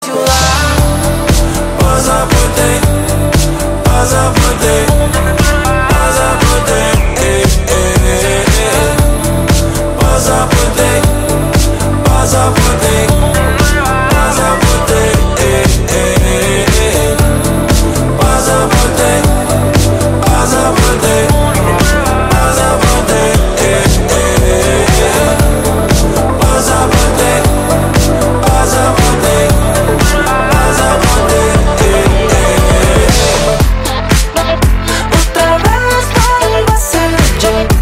Rap/Hip Hop